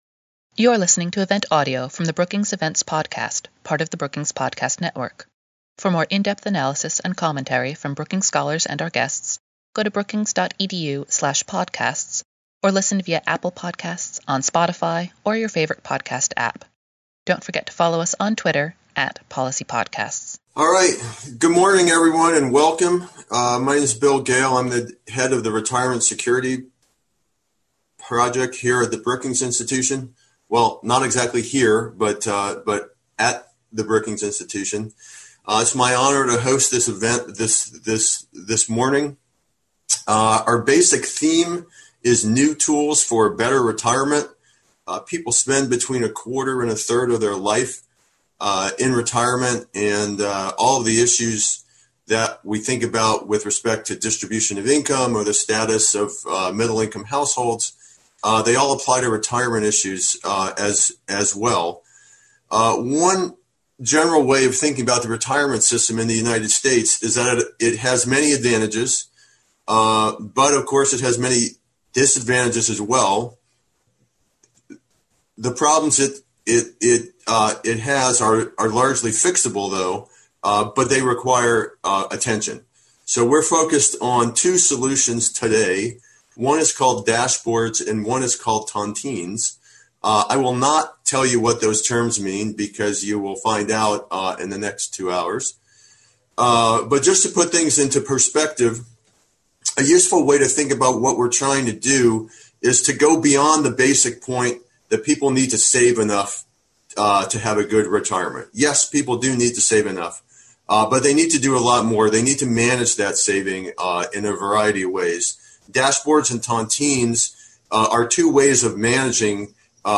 On September 17, the Retirement Security Project at Brookings hosted an event to examine new tools that could help people prepare for retirement.